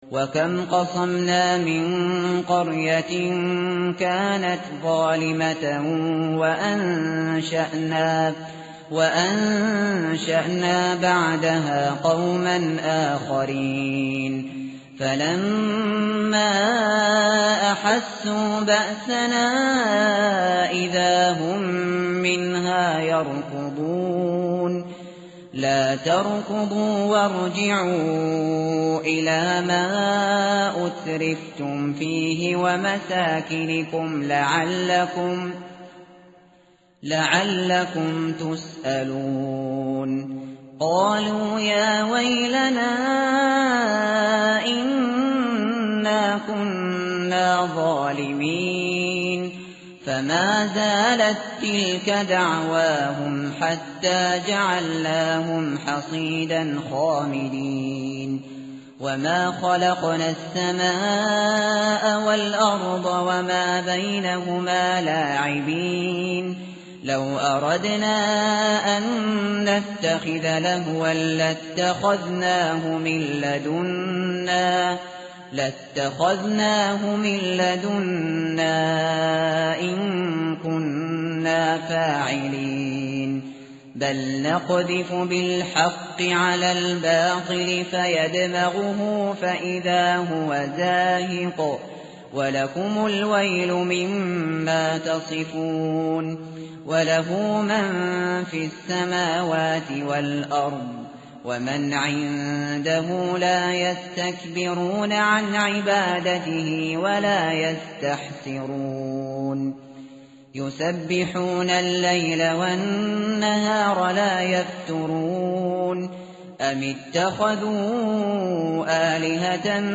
tartil_shateri_page_323.mp3